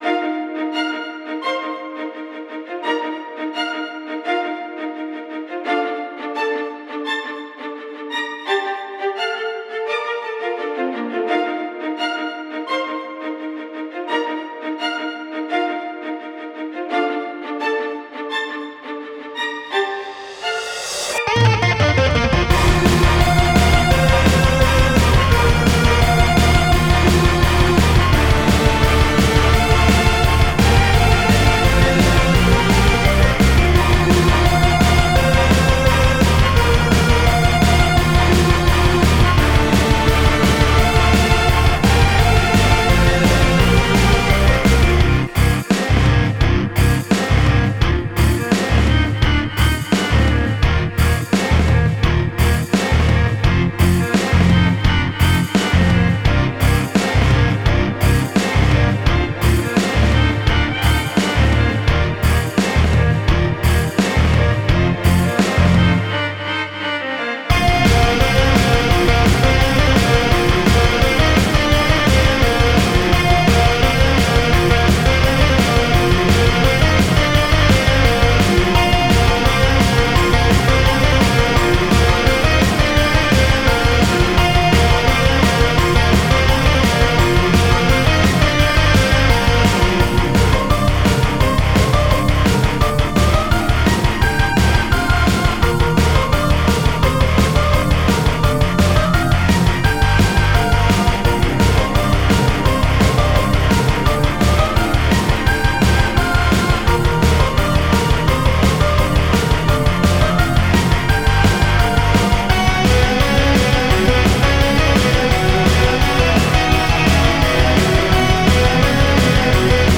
“Happy” Flowey Motif at 1:19:
Cheerful Theme Bridge at 2:15:
with full strings, electric guitar, and drums backing